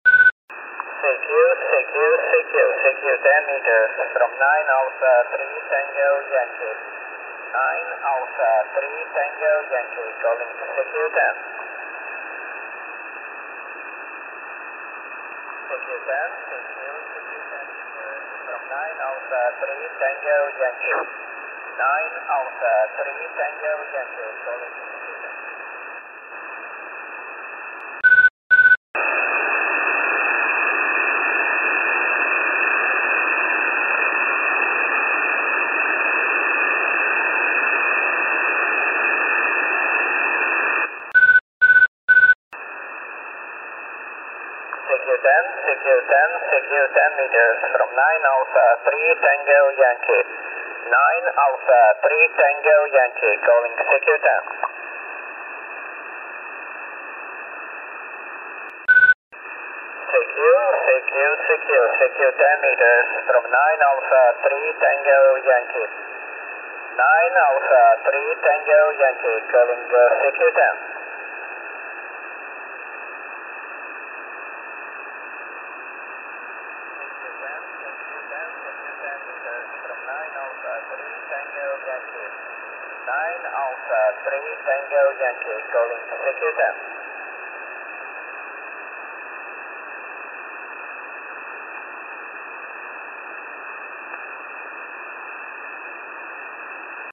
I have marked the start of the inverted L with one beep, the dipole with two beeps, and the Steppir with three.
We hear the three antennas, and then the inverted L again. The inverted L is a workable strength, but with heavy QSB on the final clip.